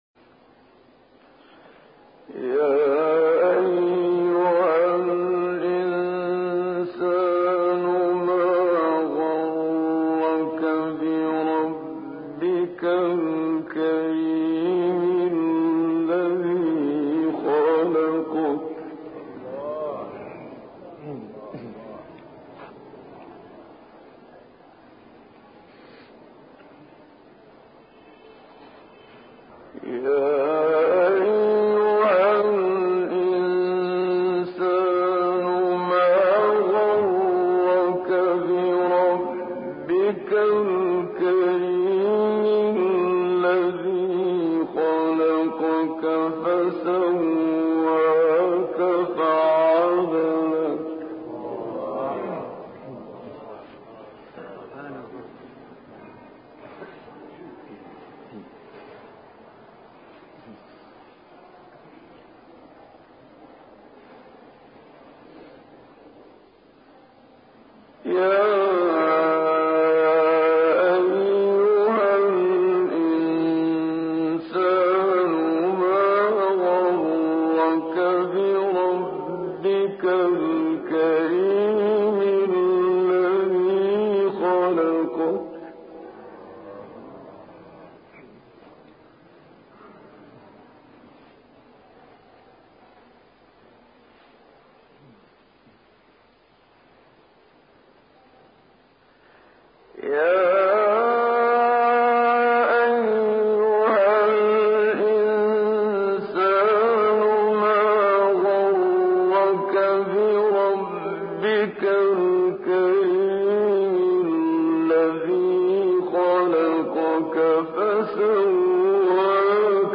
این تکرارها در ابتدا با نوعی التماس و خواهش و خیرخواهی همراه است.
سپس در مرتبه سوم با ورود در مقام رَست قدری به شنونده نهیب می‌زند و با یک لحن هشدارآمیز به او می‌گوید که نباید نسبت به پروردگار کریم خود مغرور شود. بار چهارم نیز این سوز و گداز وجود دارد و در نهایت با یک نهیب دیگر تلاوت این آیات را به اتمام می‌رساند.